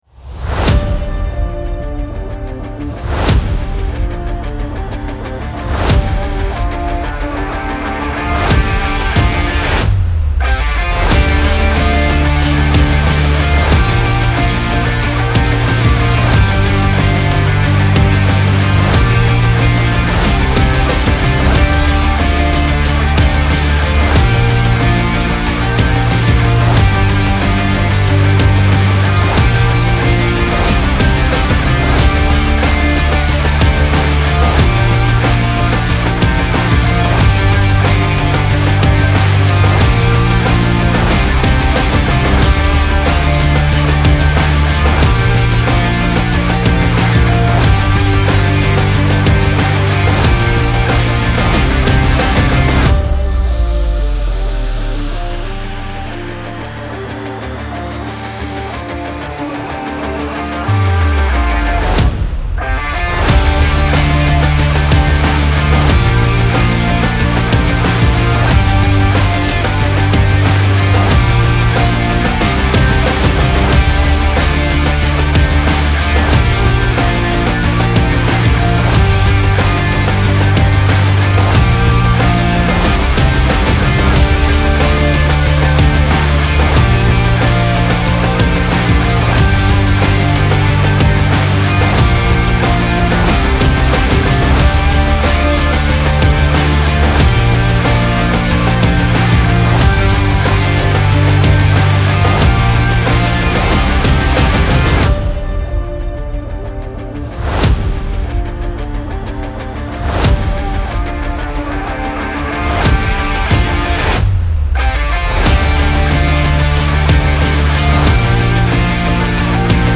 Genres: Sport-Rock-Music